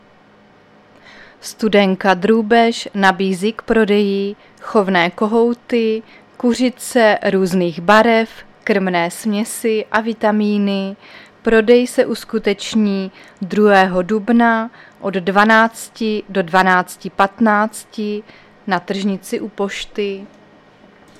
Záznam hlášení místního rozhlasu 28.3.2024
Zařazení: Rozhlas